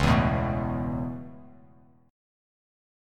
C#m7#5 chord